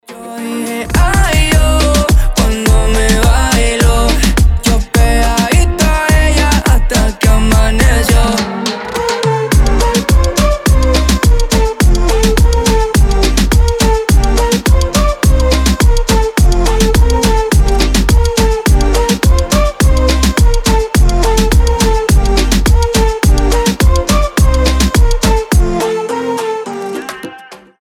• Качество: 320, Stereo
реггетон